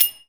percussion 12.wav